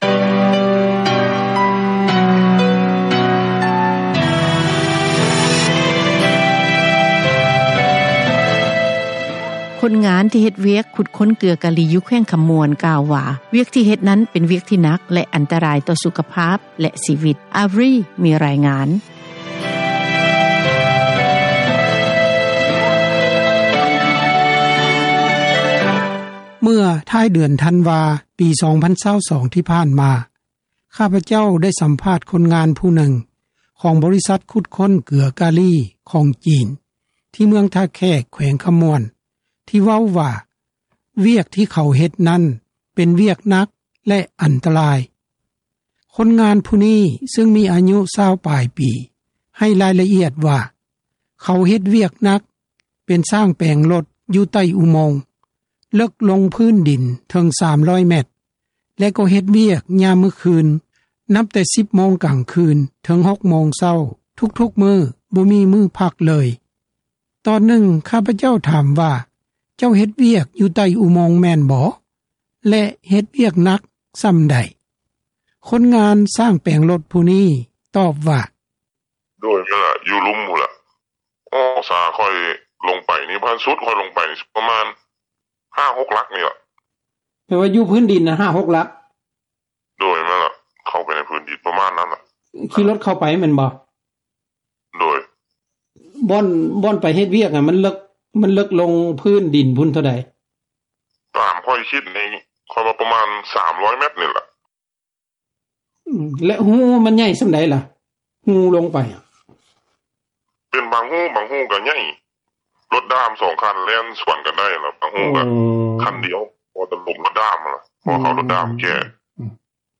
ຄົນງານຊ່າງແປງຣົຖຜູ້ນີ້ຕອບວ່າ: